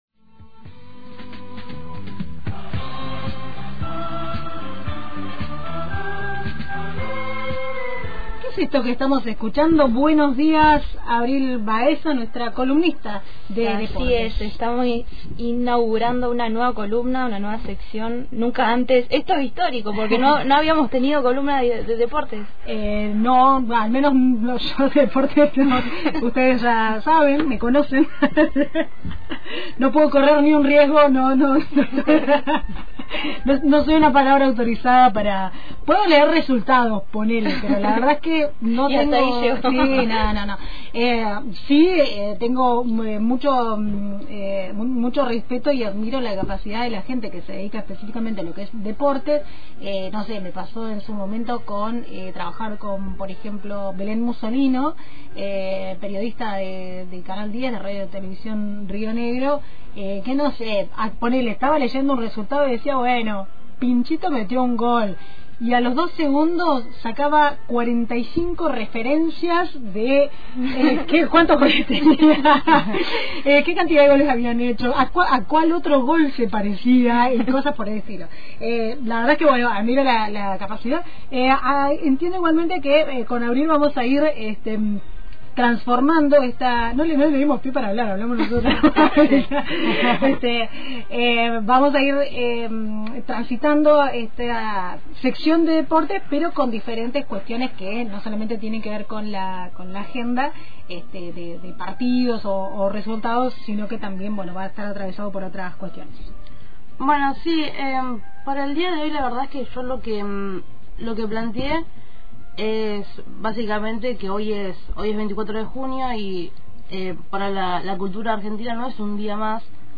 columna de deportes